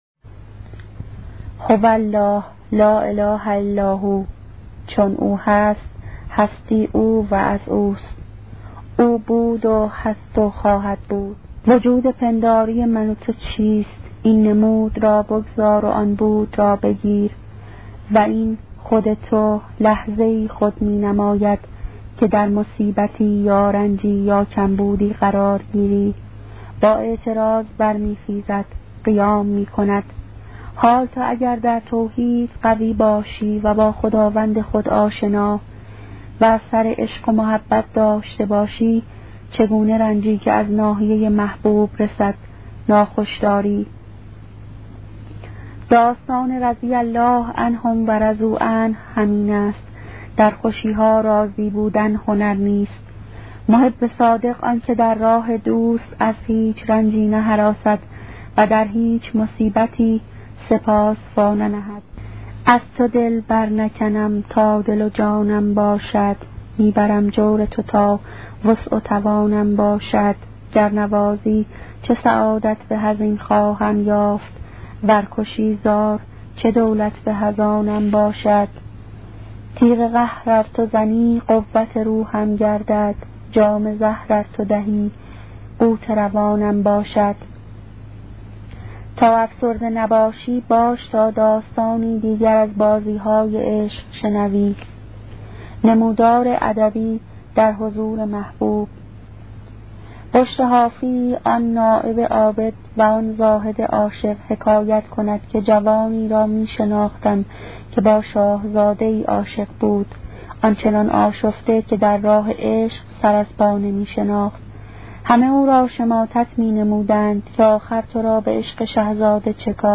کتاب صوتی عبادت عاشقانه , قسمت سیزدهم